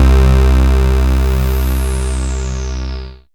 37ac01syn-c.wav